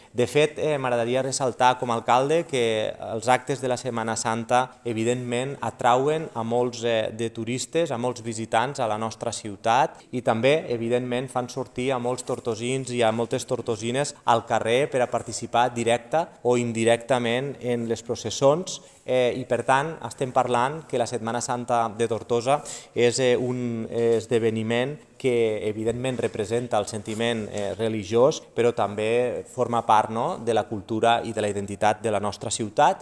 L’alcalde de Tortosa, Jordi Jordan ha fet una valoració positiva sobre tots els esdeveniments que es duran a terme, i ha assenyalat que és un gran reclam turístic.